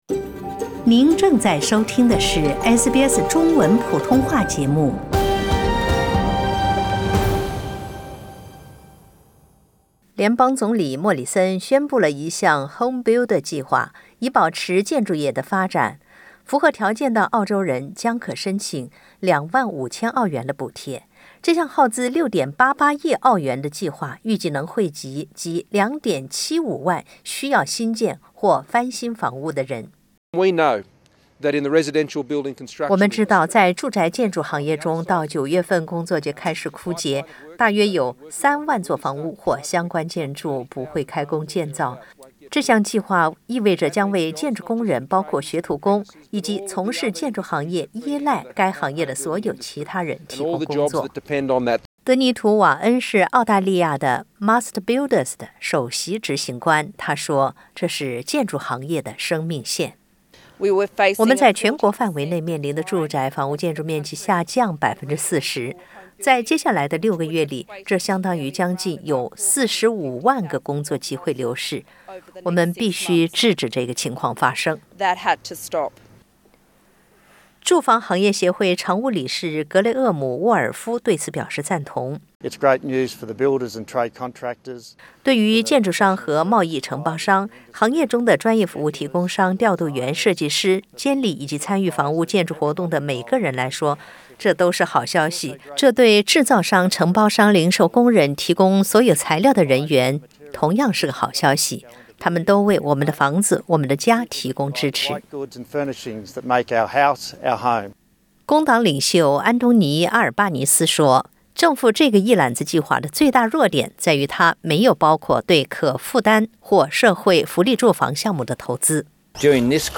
那么，HomeBuilder资助计划将起到怎样的作用？（点击上图收听报道）